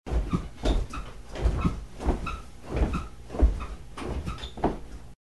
На этой странице собраны разнообразные звуки дивана: от скрипов старых пружин до мягкого шуршания обивки.
Прыжки по дивану ногами